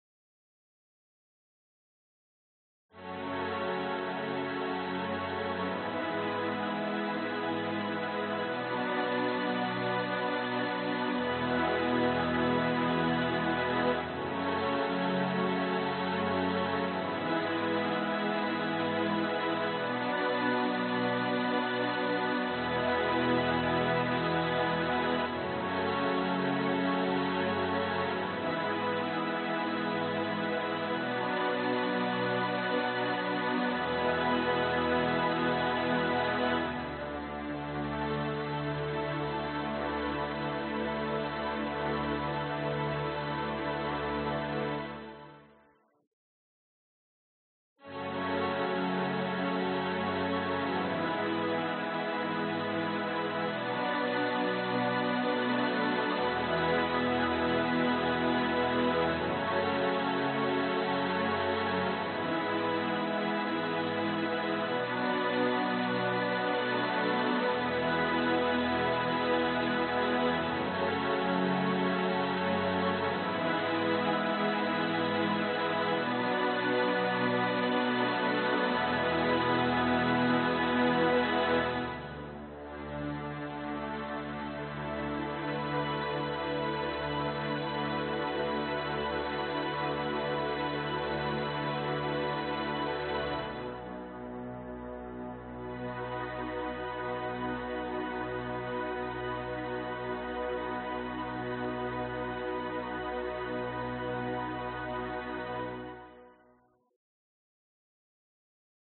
描述：大弦琴演奏小和弦
标签： 环境 寒意 低速 管弦乐 小提琴
声道立体声